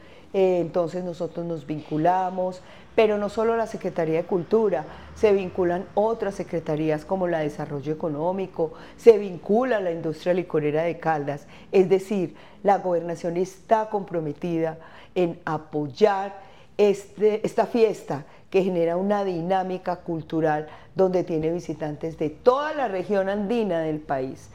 Luz Elena Castaño Rendón, secretaria de la Secretaría de Cultura de Caldas.